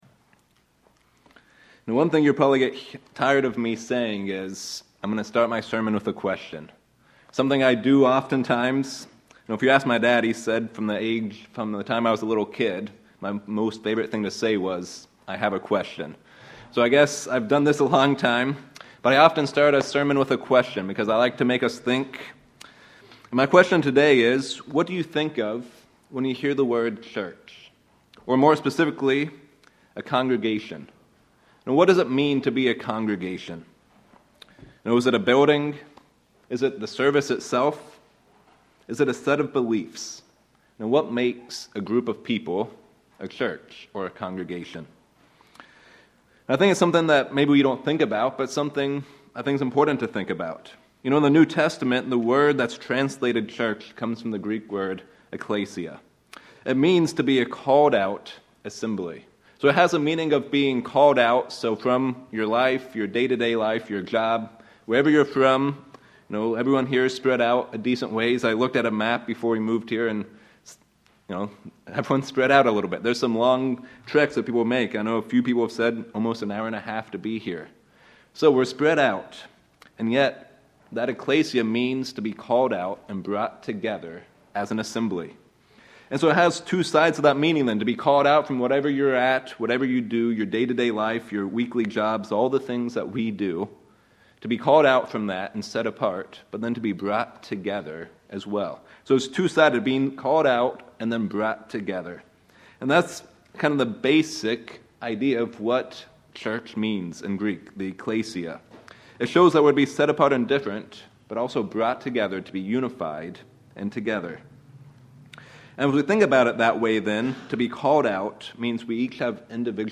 Sermons
Given in Reno, NV Sacramento, CA